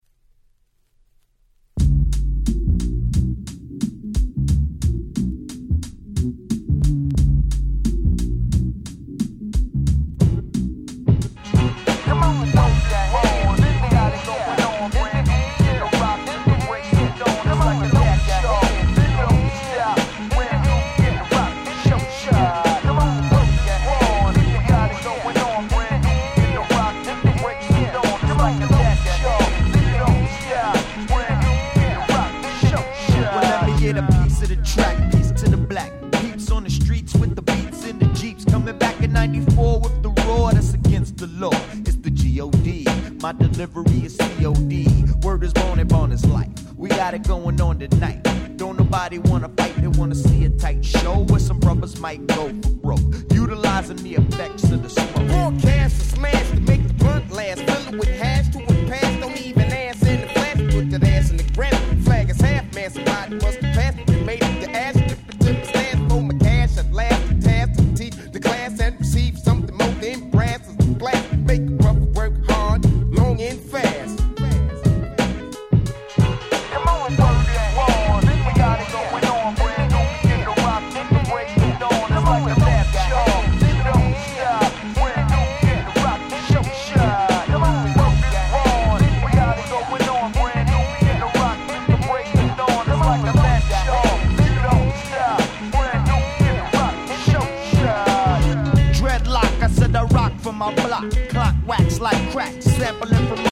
94' Smash Hit Hip Hop !!
90's Boom Bap ブーンバップ